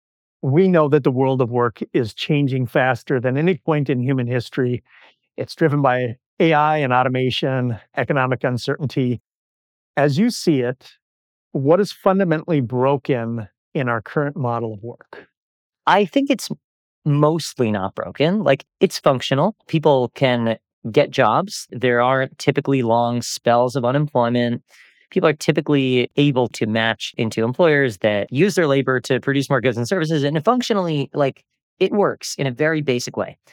This sample demonstrates a complete edit of a real podcast segment, combining all the techniques and more.
After (Edited Master):
A professionally edited podcast episode ready for publishing.
Edited-Mastered-Podcast-Sample.wav